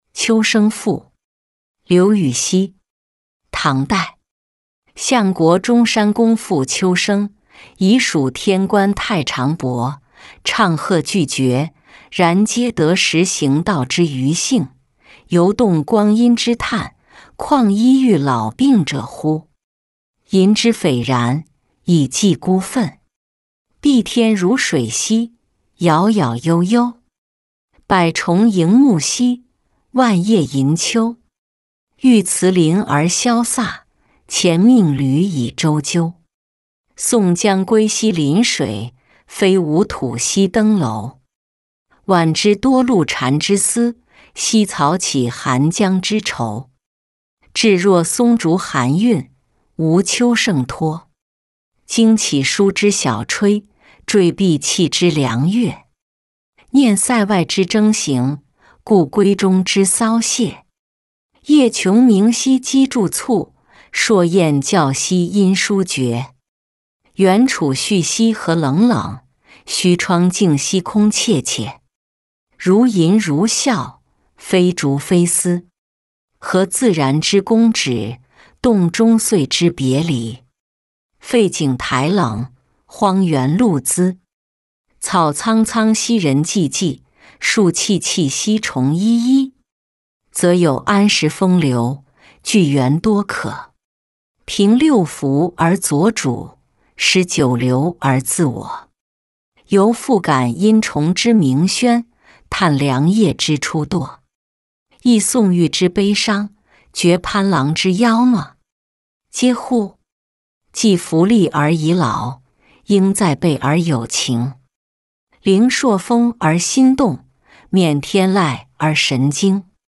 秋声赋-音频朗读